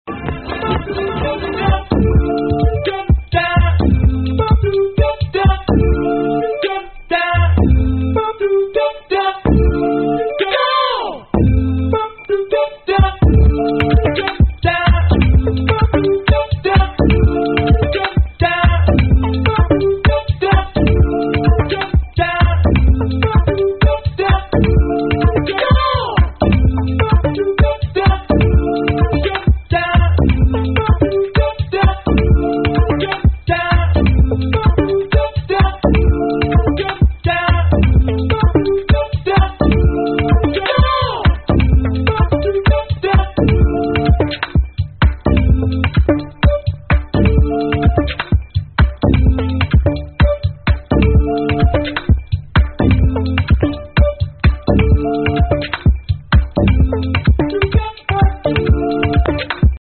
Ghost Smilie ID Minimal Track please